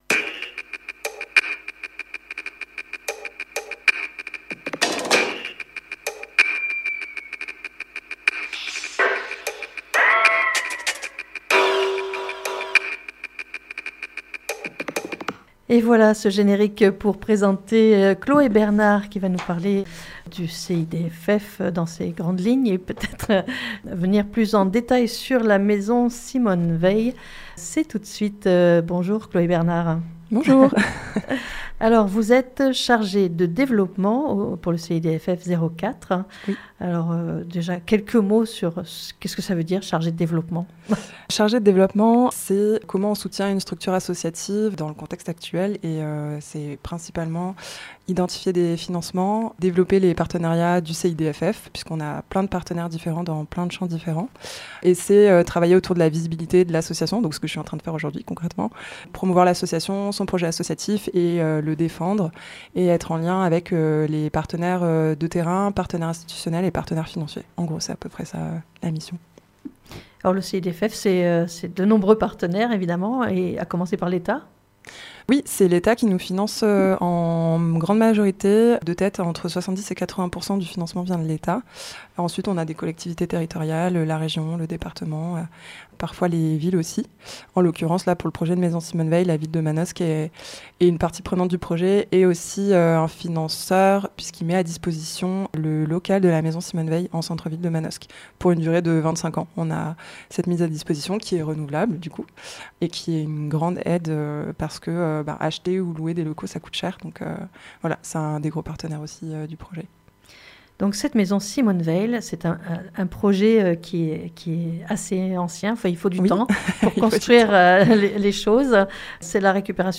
Inauguration de la Maison des Femmes Simone Veil à Manosque le 8 mars 2025.